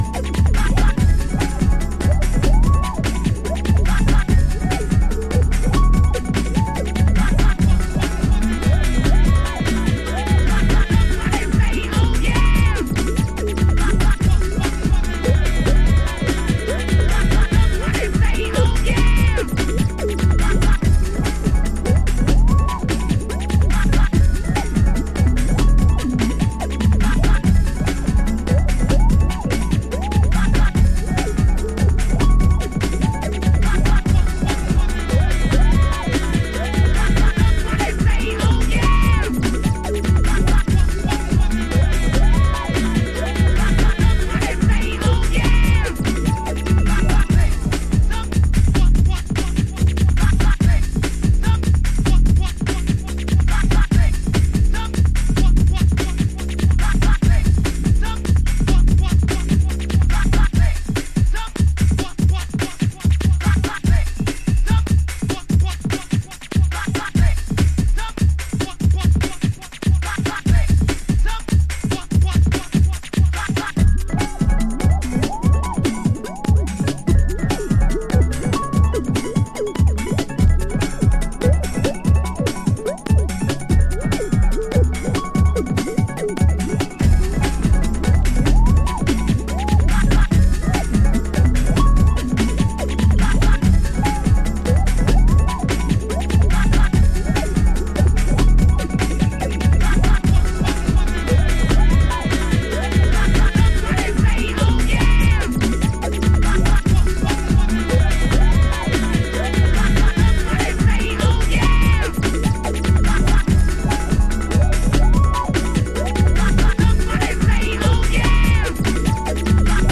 Early House / 90's Techno
ドラムンベース気味にファンクしています。この人の問答無用のジャンク感は素敵ですね。